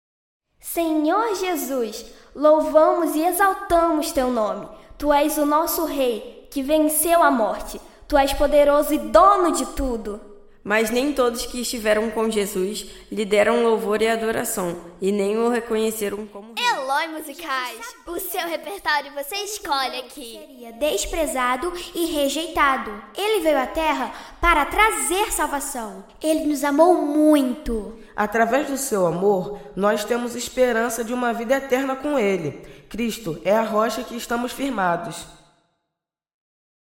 musical de Páscoa infantil, simples e moderno